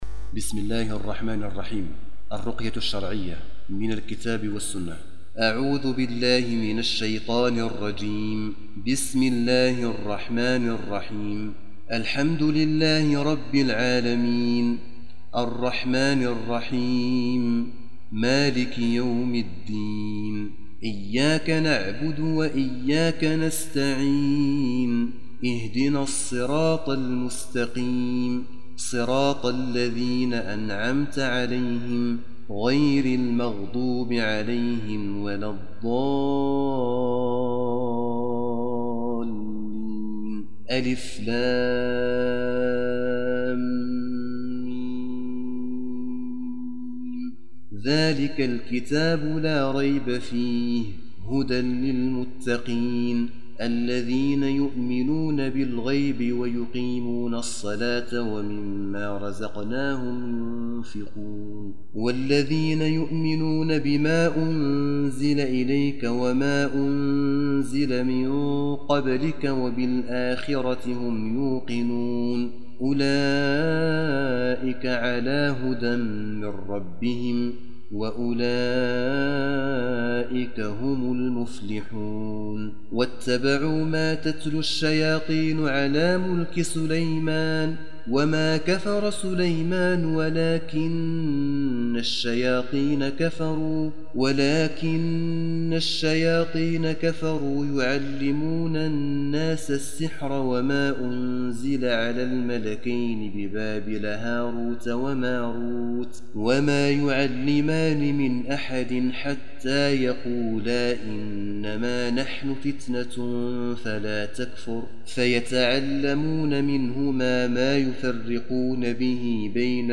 تلاوة للرقية الشرعية الشاملة